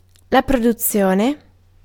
Ääntäminen
Ääntäminen US
IPA : /ɛksɪˈbɪʃən/